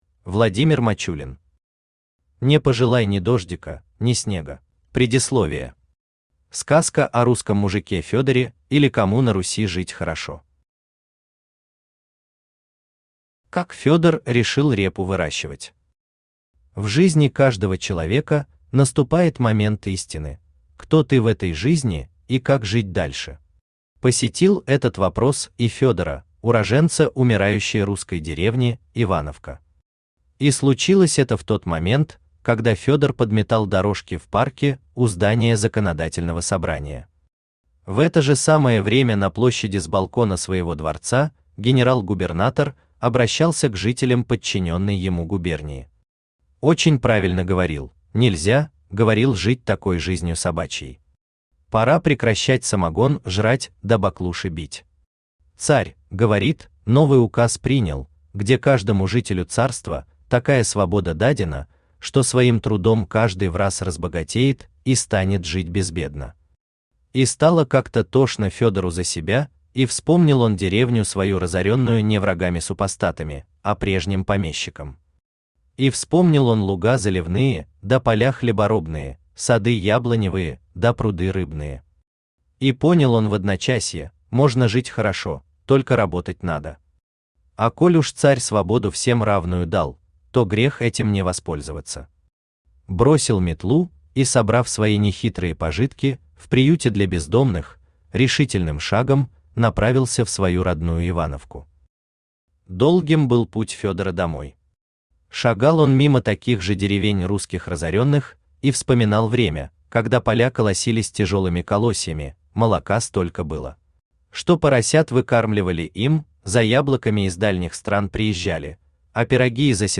Аудиокнига Не пожелай ни дождика, ни снега | Библиотека аудиокниг
Aудиокнига Не пожелай ни дождика, ни снега Автор Владимир Иванович Мачулин Читает аудиокнигу Авточтец ЛитРес.